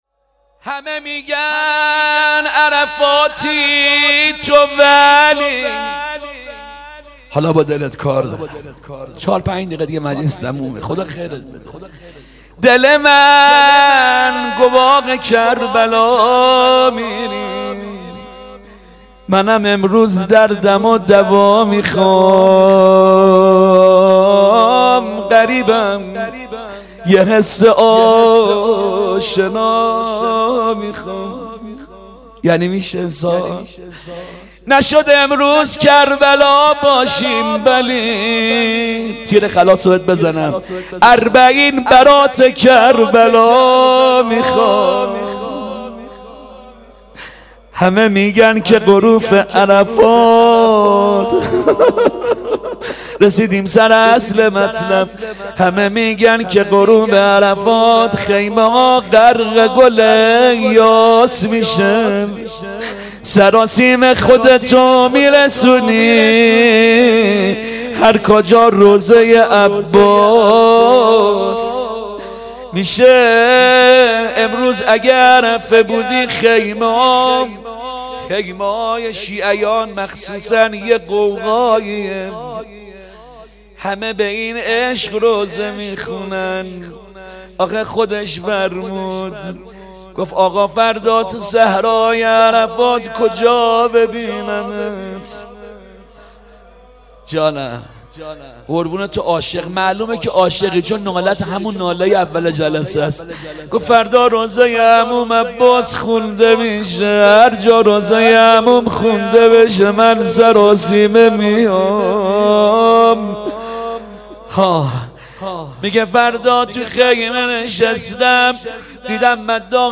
روضه حضرت عباس علیه السلام